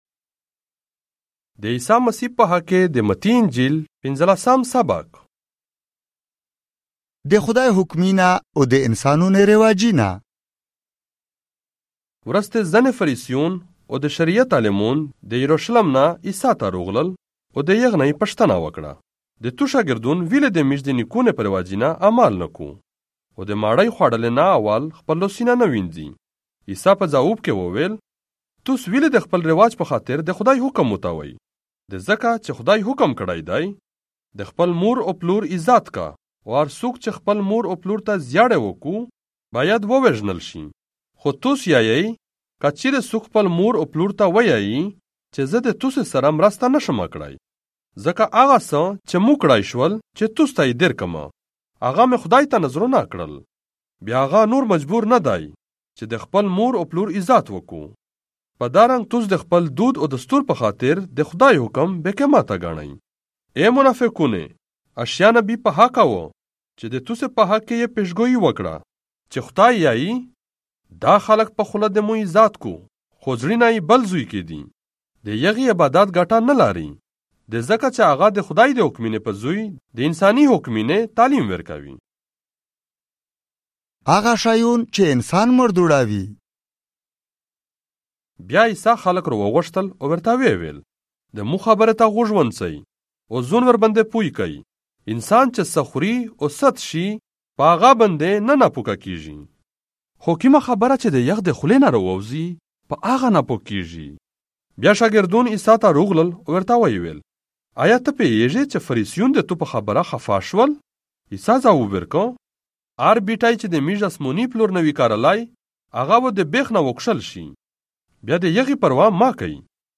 دې عيسیٰ مسيح په حق کې دې متي انجيل - پينځلاسام ساباق، په پشتو ژبه، مرکزي (آډیو) ۲۰۲۵